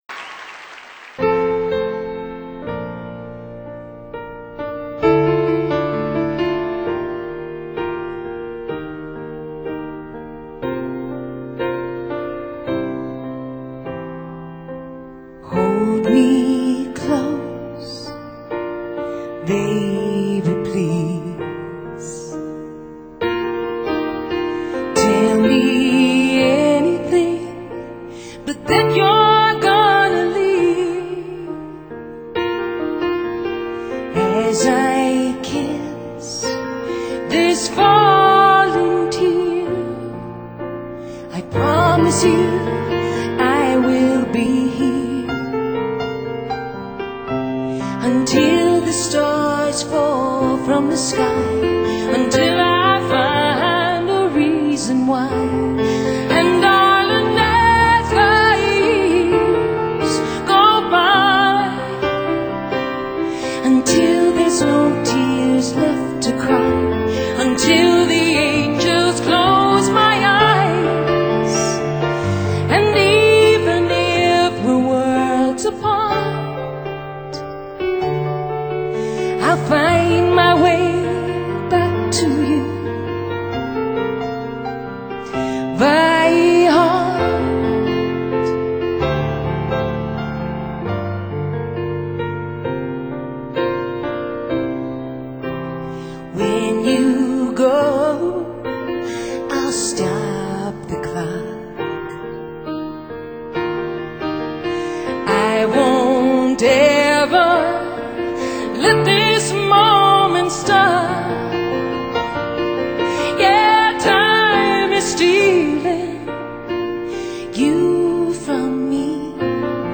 专辑主要是在2000年3月的盐湖城音乐会上现场录制的，再一次显示了他不一般的艺术天份。